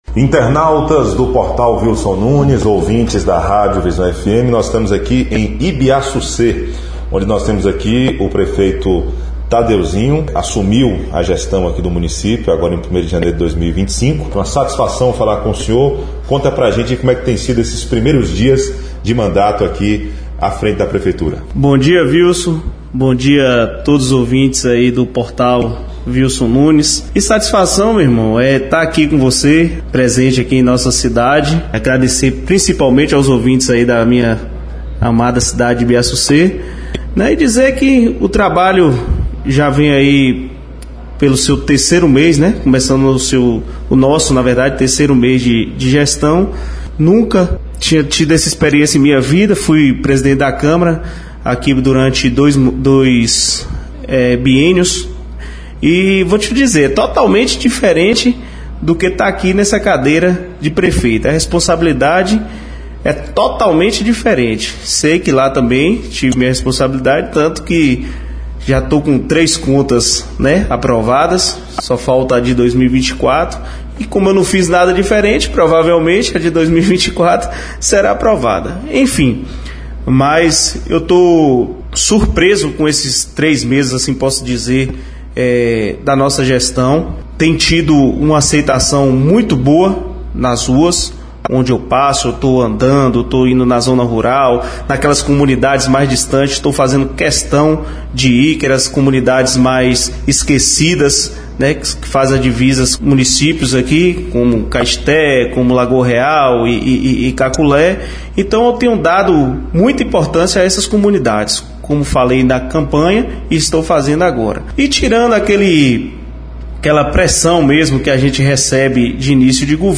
O prefeito de Ibiassucê, no sudoeste baiano, Tadeuzinho Prates, concedeu entrevista ao Portal Vilson Nunes na manhã desta segunda-feira (10), no gabinete da Prefeitura Municipal. Durante a conversa, ele fez um balanço dos primeiros meses de governo e destacou prioridades para a gestão.